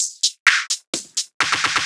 Fill 128 BPM (30).wav